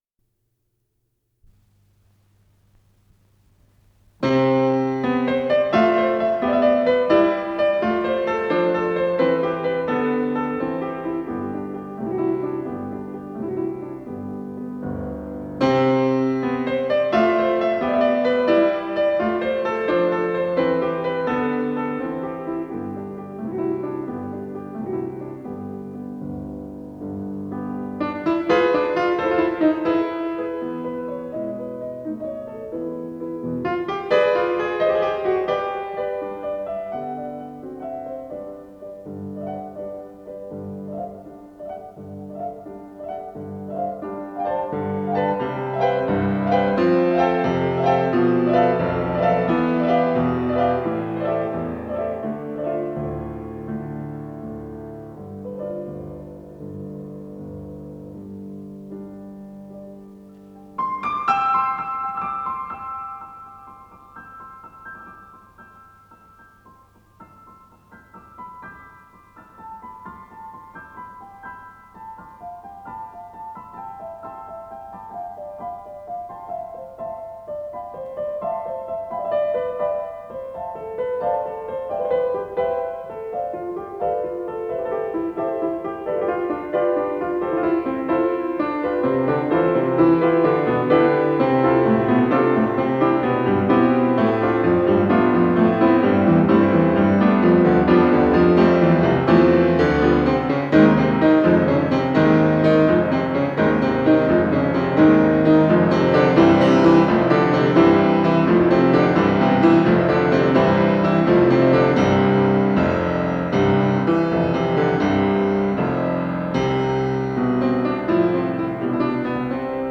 с профессиональной магнитной ленты
Название передачиЛирические пьесы для фортепиано, соч. 54
ИсполнителиВиктор Мержанов - фортепиано
ВариантДубль моно